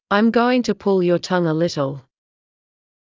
ｱｲﾑ ｺﾞｰｲﾝｸﾞ ﾄｩ ﾌﾟﾙ ﾕｱ ﾀﾝｸﾞ ｱ ﾘﾄﾙ